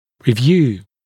[rɪ’vjuː][ри’вйу:]обзор, обозрение; осматривать, производить осмотр, пересматривать